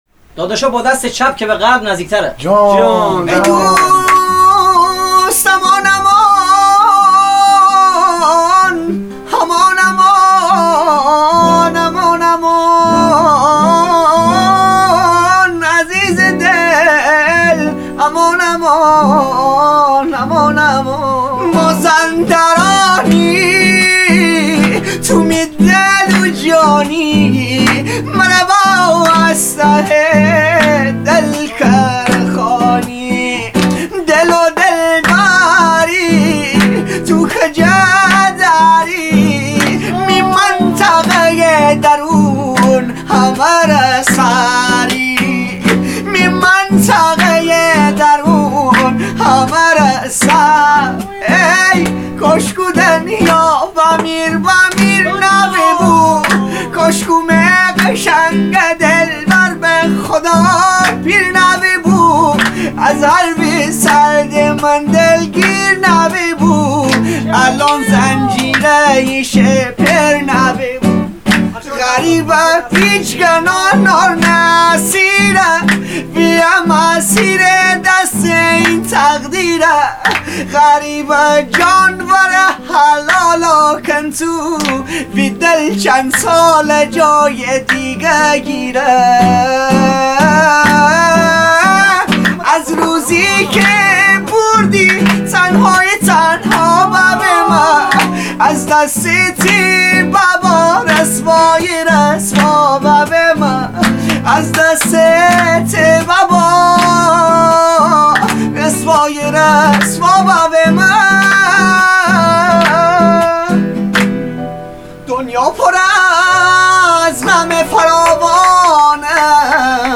ریمیکس گیتاری